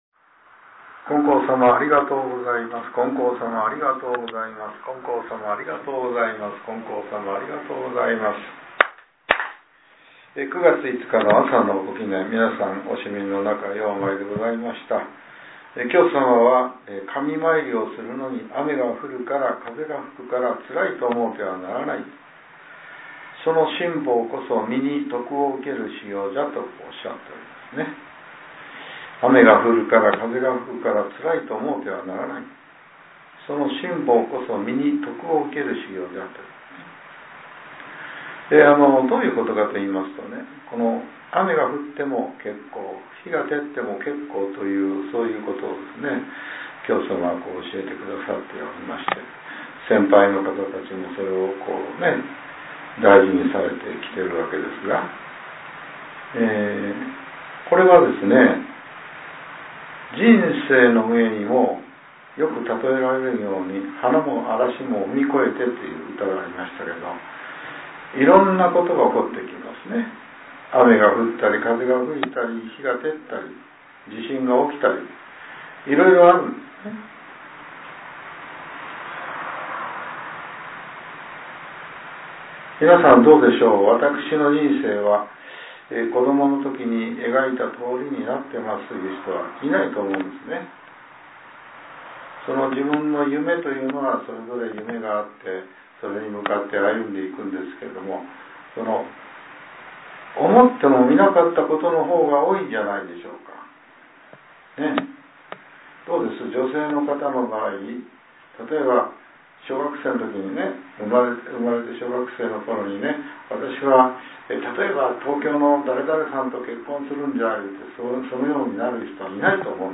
令和７年９月５日（朝）のお話が、音声ブログとして更新させれています。